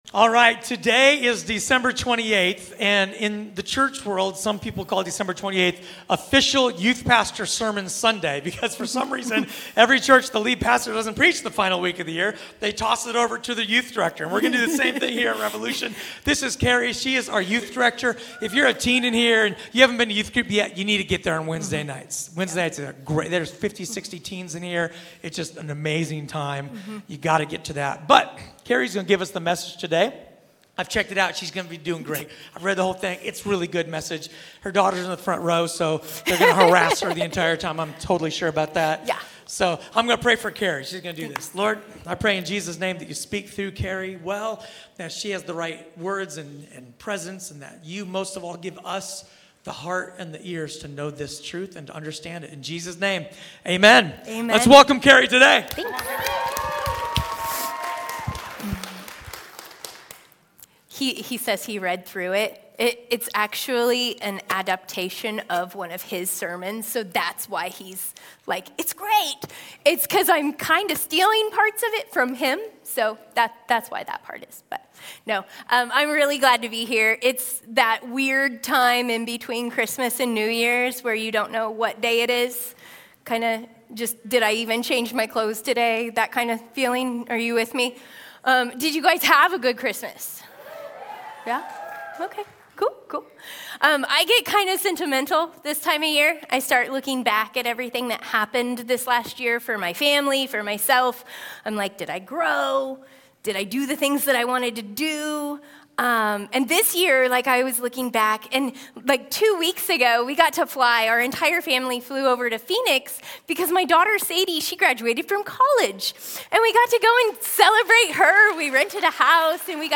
A sermon from the series "Guest."